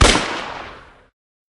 pistol_fire_01.ogg